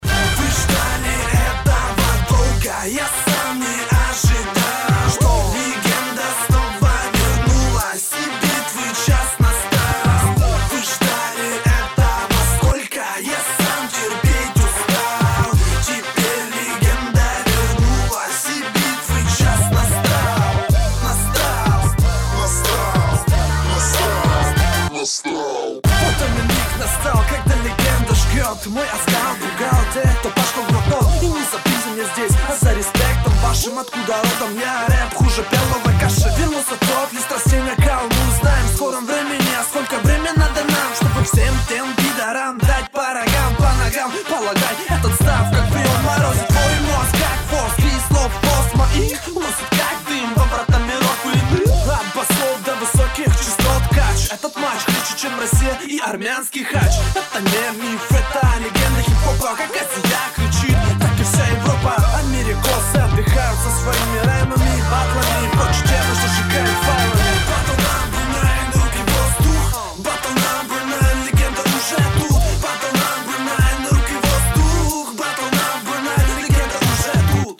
ой..под такой бит не использовать поставленный голос и подачу... просто непростительно